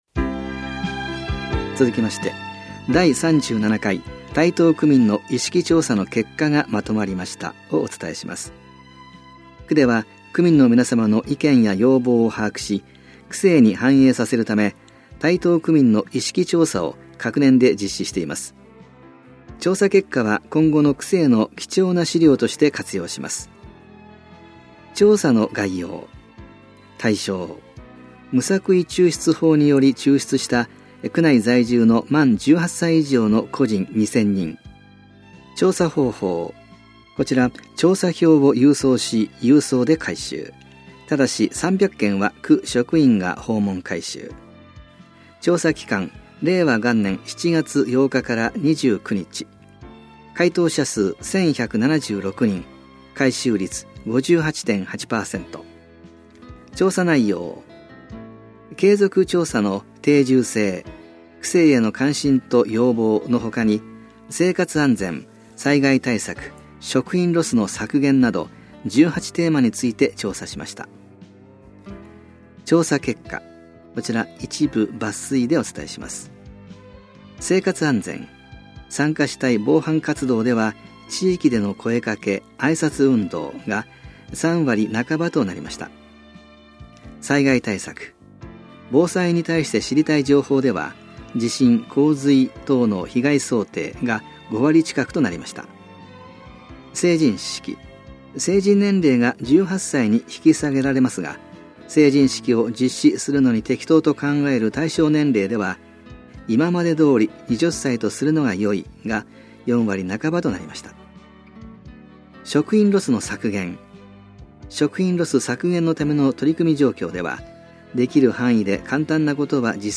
広報「たいとう」令和2年1月20日号の音声読み上げデータです。